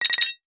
NOTIFICATION_Metal_05_mono.wav